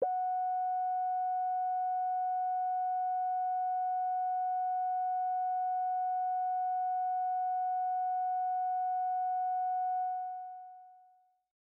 标签： F4 midinote66 AkaiAX80 synthesizer singlenote multisample
声道立体声